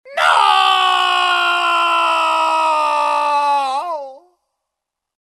Звуки мужского крика
Звук мужского крика No